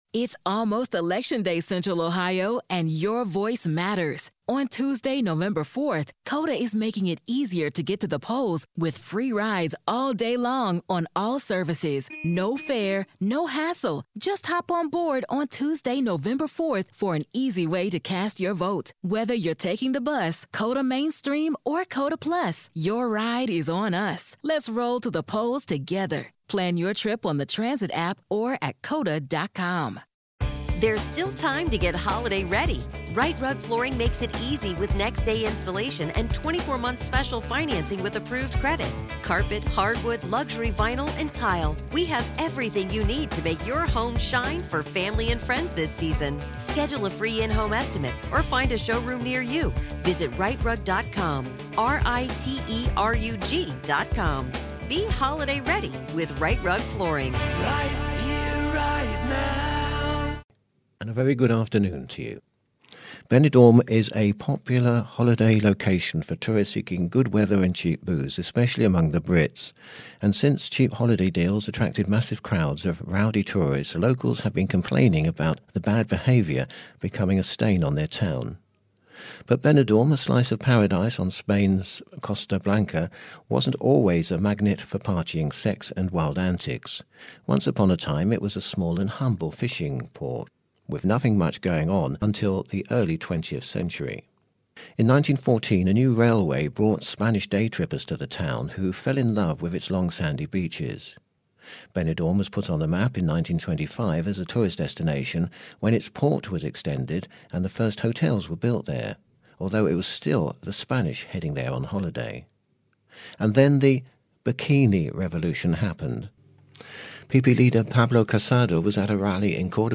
The latest Spanish News Headlines in English: April 8th